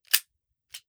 38 SPL Revolver - Dry Trigger 003.wav